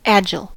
agile-2: Wikimedia Commons US English Pronunciations
En-us-agile-2.WAV